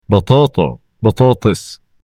یکی از اولین چیزهایی که متوجه شدم، این بود که برای گفتن “سیب زمینی به عربی”، دو کلمه رایج وجود دارد: بطاطا (Baṭāṭā) و بطاطس (Baṭāṭis).
potato-in-arabic.mp3